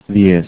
If you've worked with the sound chart, you can visualize how "diez" sounds exactly like English "the yes" - but said very rapidly and slurred together.